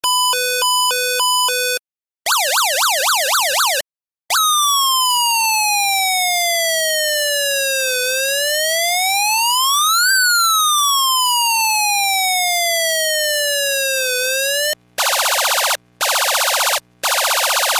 ４種類のサウンドを発生する基板です。
上記動作確認回路でパソコンに取り込んでＳｏｕｎｄ音を録音しました。
各ファイルはＥｍｅｒｇｅｎｃｙ　Ａｌａｒｍ （ピーポピーポ）　→　約１ｓ休止　→　Ａｌａｒｍ（ピヨピヨ）　→　約１ｓ休止　→Ｆｉｒｅ　Ａｌａｒｍ（ウ−ウ−）　→　約１ｓ休止　→Ｍａｃｈｉｎｅ　Ｇｕｎ （ダッダッダッ）の順番で録音しています。
２２０ｋΩ